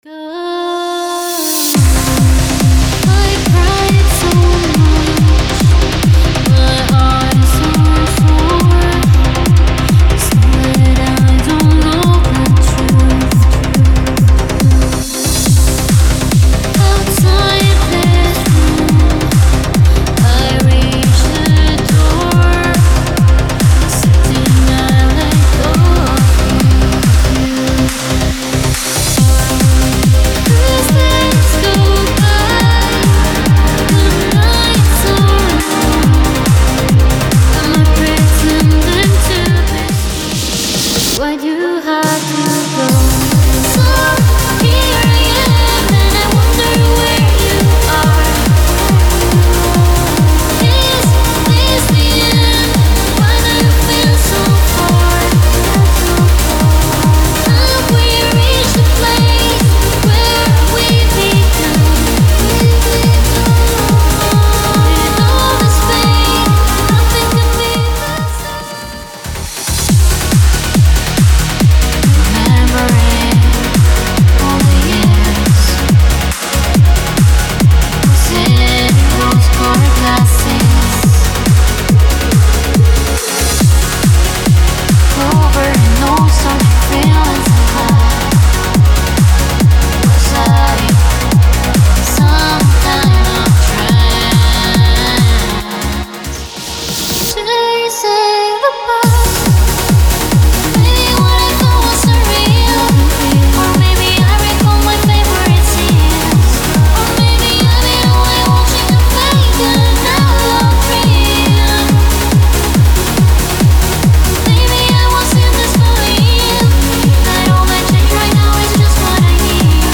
Trance Uplifting Trance Vocals
65 dry vocal files
65 wet vocal files
BPM:140
Gender: Female
Tone-Scale: C Minor, D# Minor, F Minor